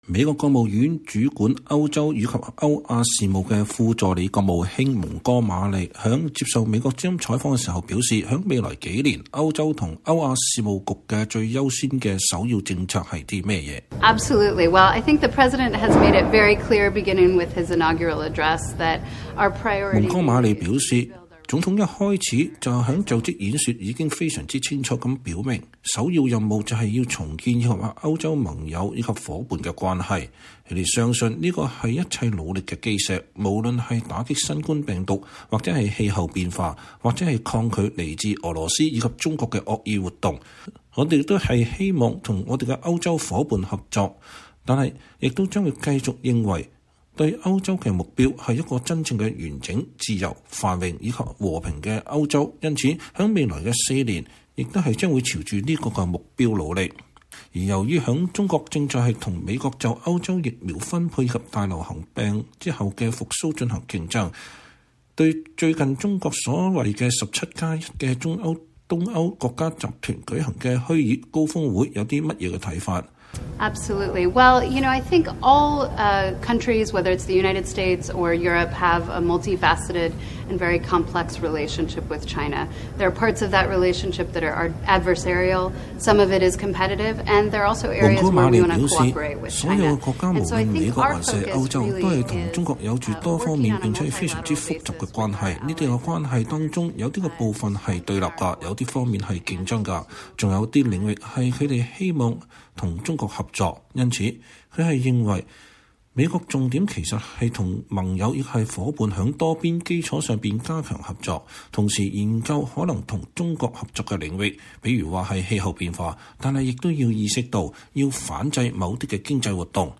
專訪美國務院官員：拜登政府放眼重建美歐關係反制中國侵犯人權